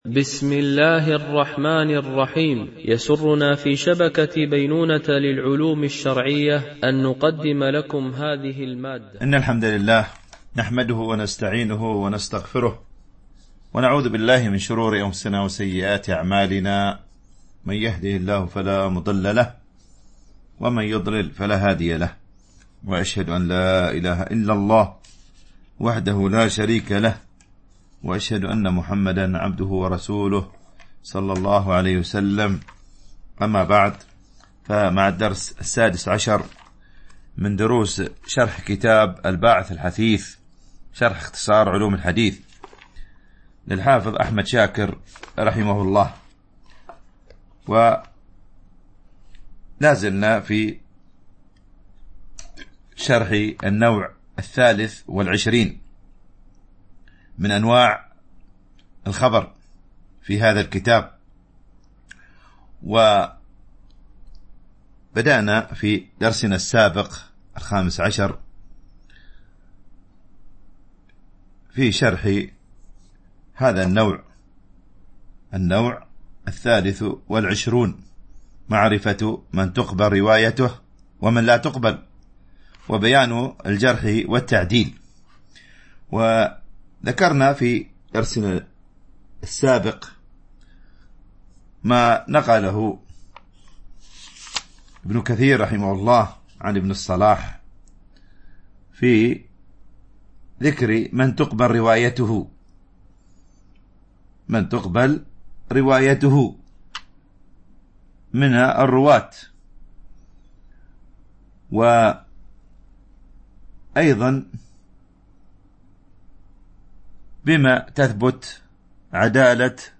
الدرس 16
التنسيق: MP3 Mono 22kHz 32Kbps (CBR)